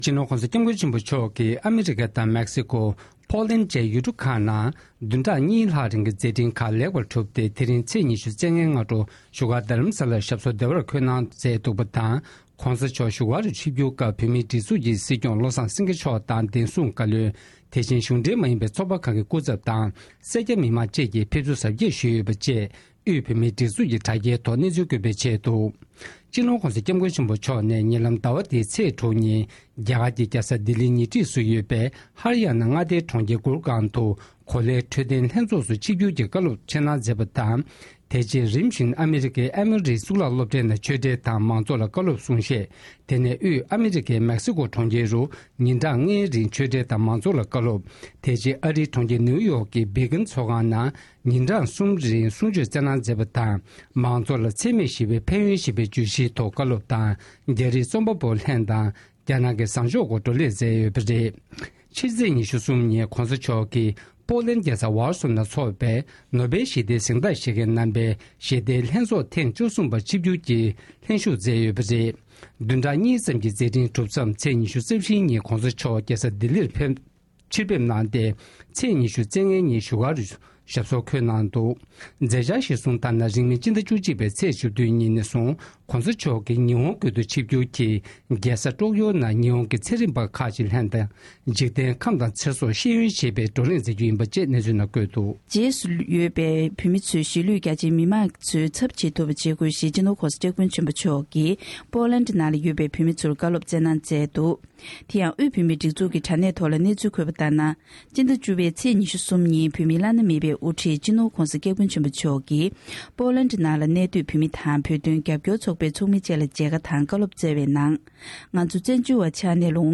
གཉིས་ཀྱིས་རིམ་པས་སྙན་སྒྲོན་གནང་བར་གསན་རོགས།།